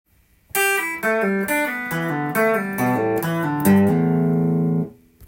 プリング練習tab譜
譜面通り弾いてみました